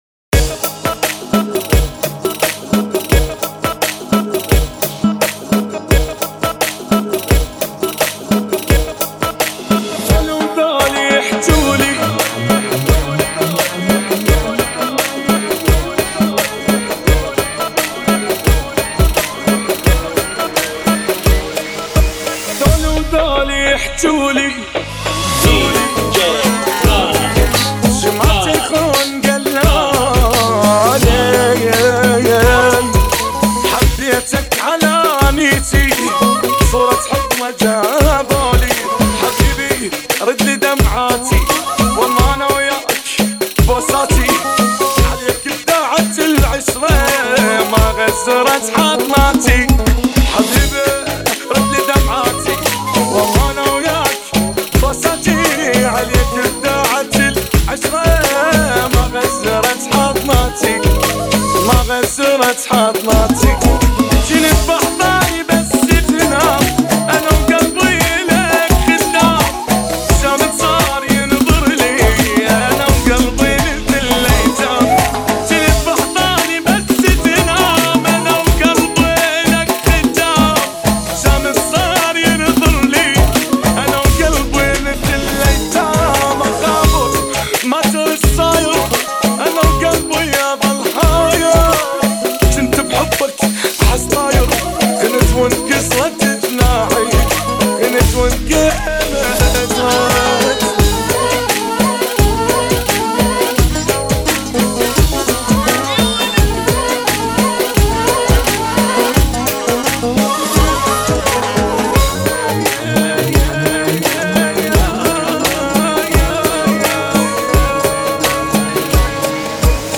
86 Bpm